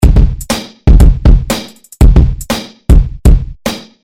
描述：有踢腿、帽子和小鼓的变化
标签： 120 bpm Breakbeat Loops Drum Loops 344.58 KB wav Key : Unknown
声道单声道